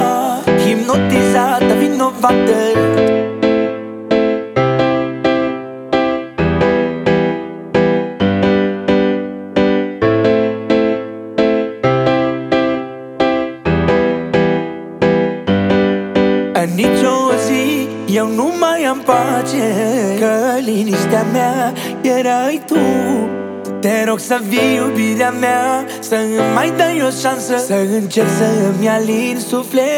Жанр: Музыка мира